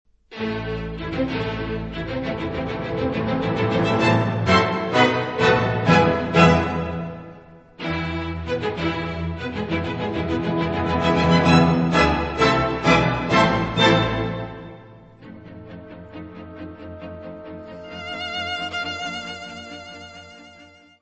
: stereo; 12 cm + folheto
Music Category/Genre:  Classical Music